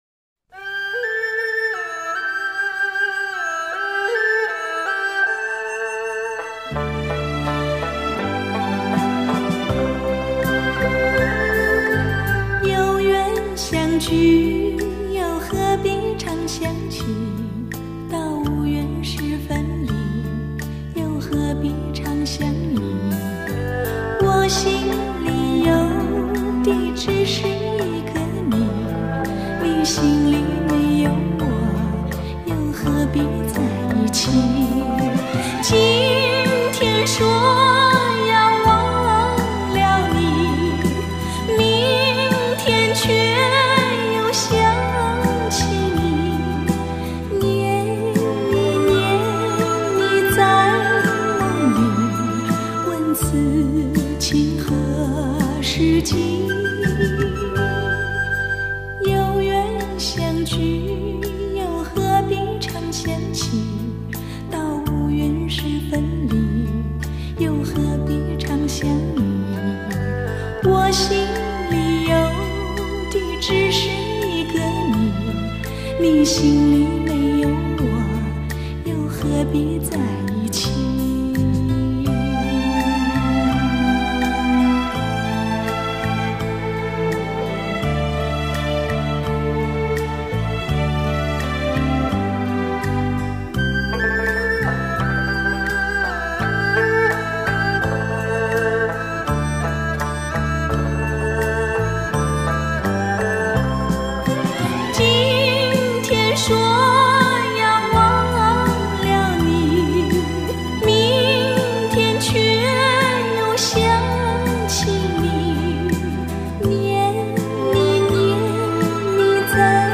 版本: 国语专辑1CD 共5CD